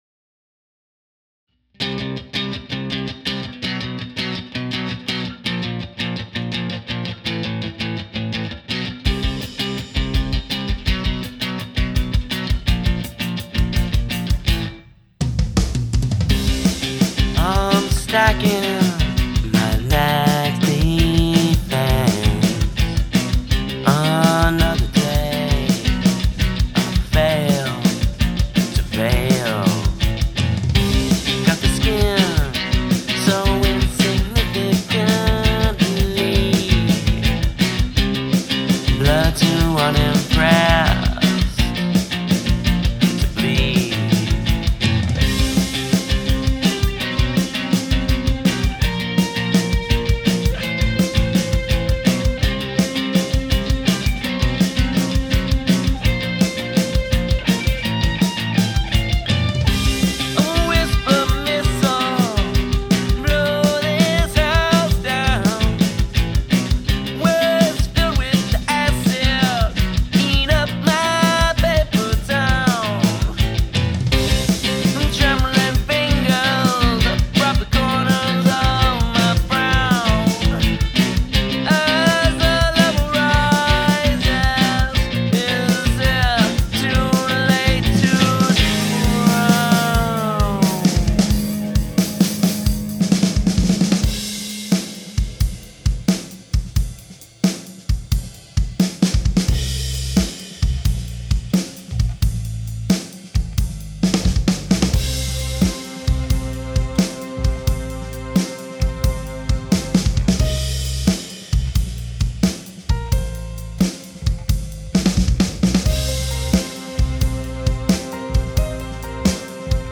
Challenge: Use of a Breakdown
Strings added on the airplane flight home.
5/4 "take 5" beat descending C, B, A#, A